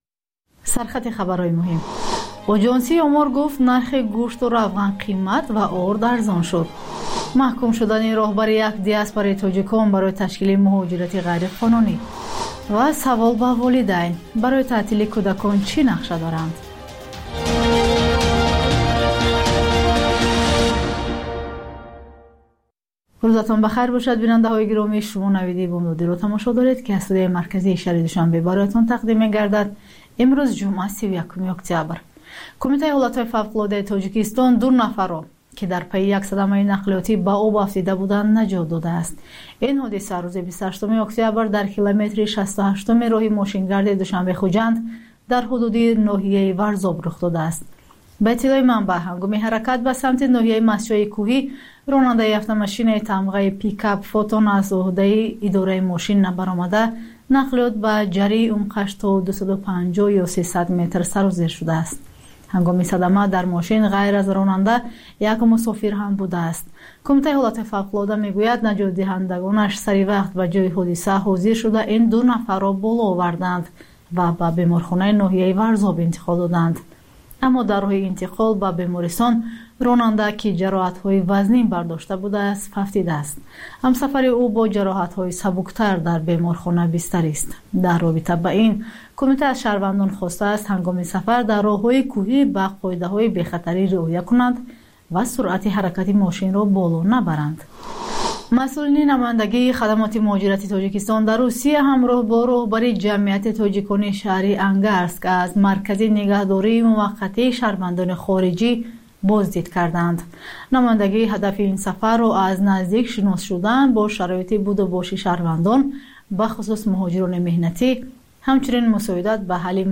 Пахши зинда
Маҷаллаи хабарӣ